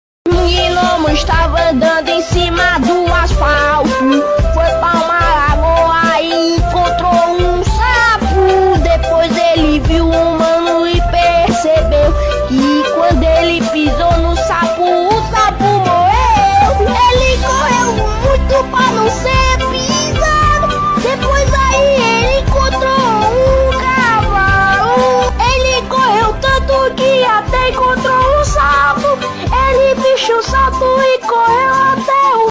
alarm4.wav